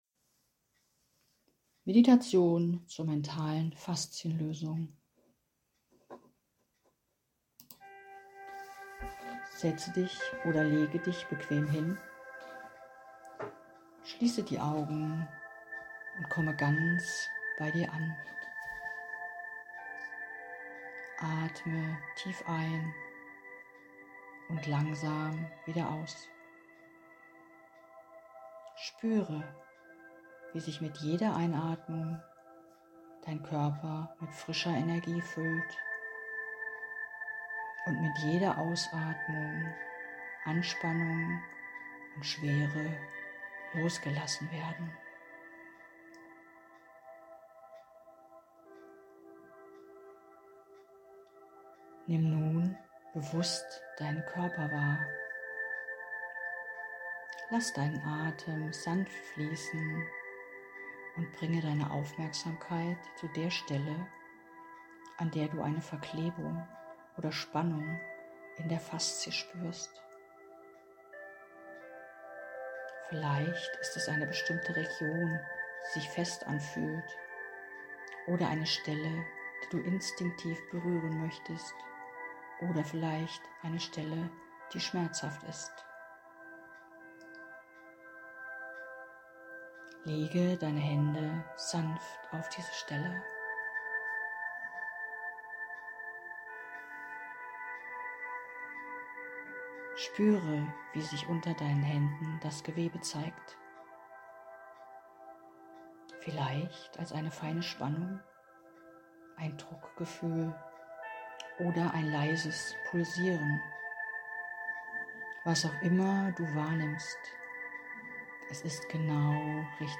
Meditation zum mentalen Faszien lösen
Faszienmeditation.mp3